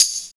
18 TAMB.wav